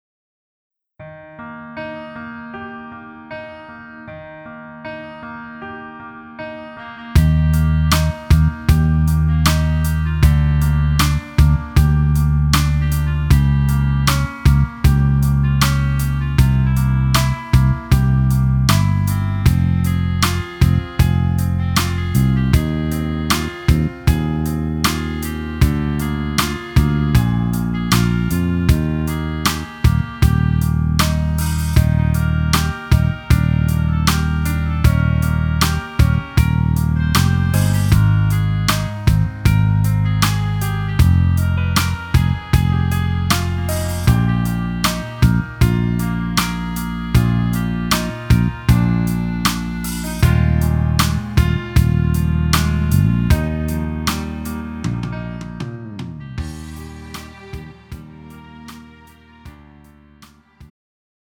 음정 -1키 4:27
장르 가요 구분 Pro MR